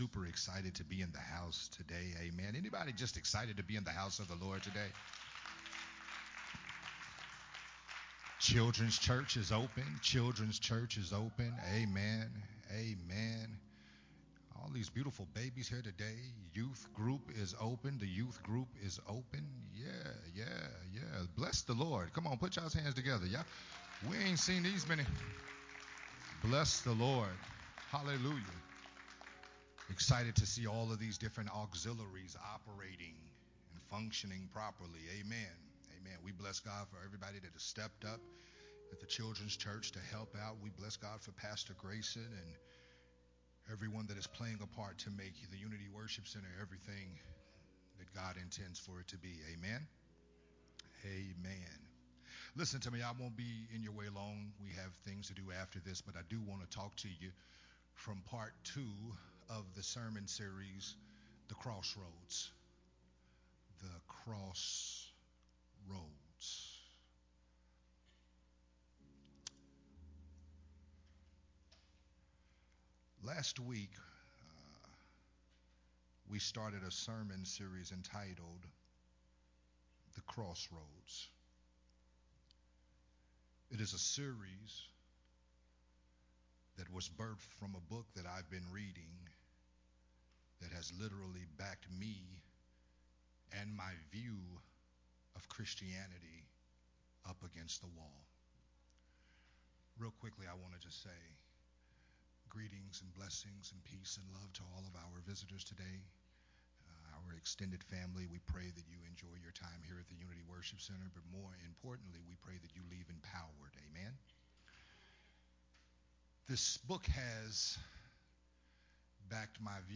a sermon
recorded at Unity Worship Center